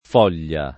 foglia [